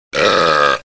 Звук - Отрыжка
Отличного качества, без посторонних шумов.